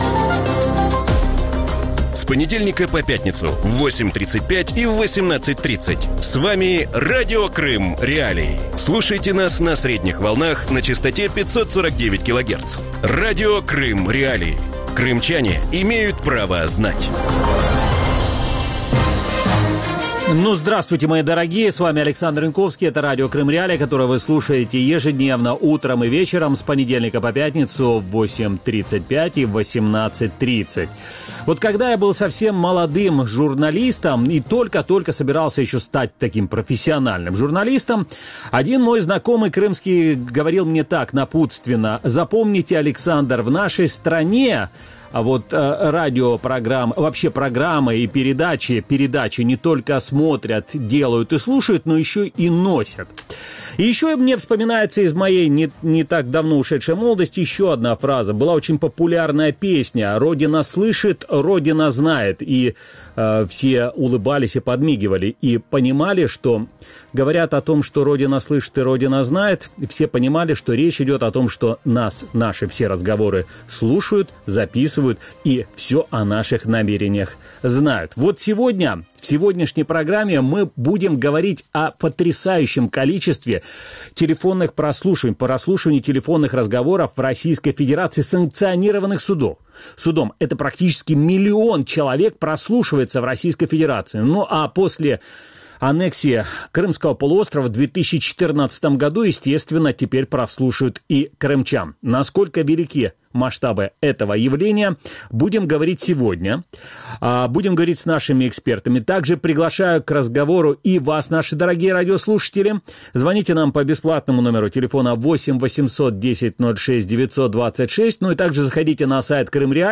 У вечірньому ефірі Радіо Крим.Реалії обговорюють методи, які Федеральна служба безпеки використовує для стеження за росіянами. На що здатні російські спецслужби, яка кількість розмов і листування прослуховується щороку і як себе вберегти від Великого брата?